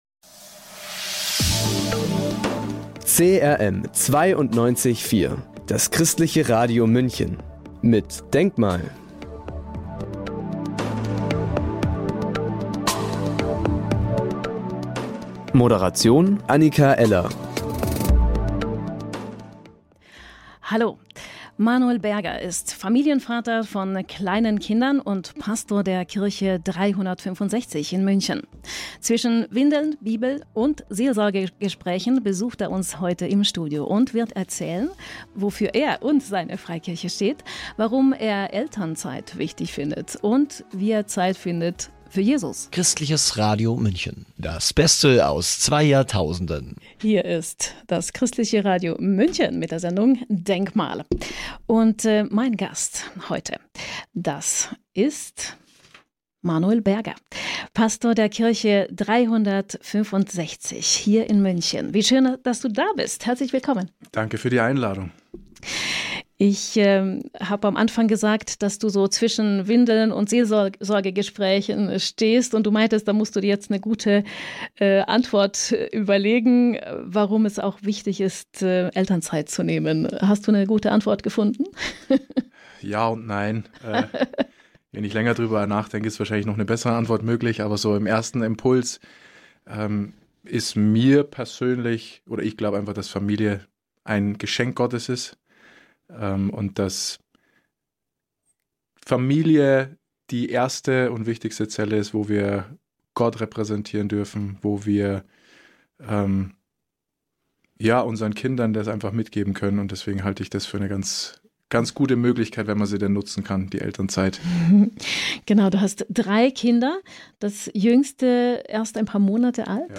Zwischen Windeln, Bibel und Seelsorgegesprächen besuchte er uns heute im Studio. Er erzählt seine Geschichte: Vom Mitläufer mit einem Doppelleben und hin zu einem Engagement, was dazu führt, dass er Pastor wird.